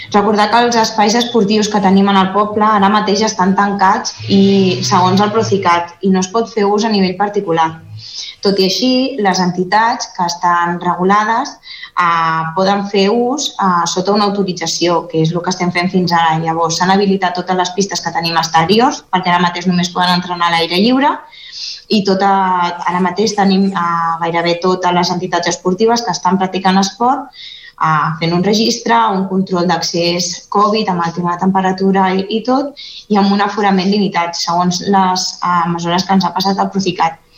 En parla Sonia González regidora d’esports.